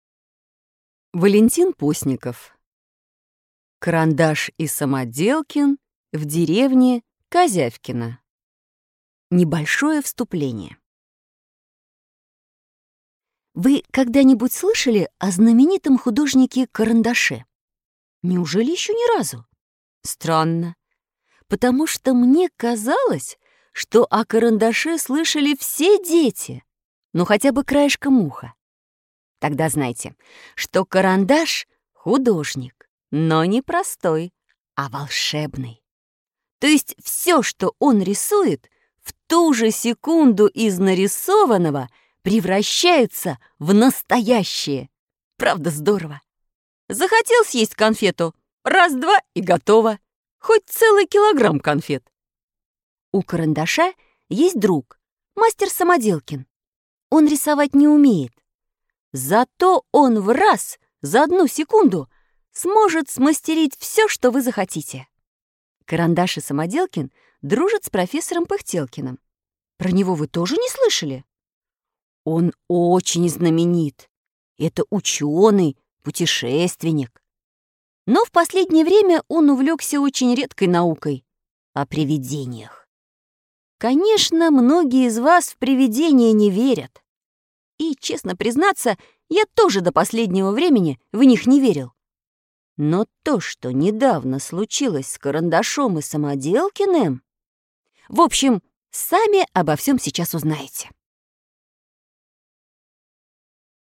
Аудиокнига Карандаш и Самоделкин в деревне Козявкино | Библиотека аудиокниг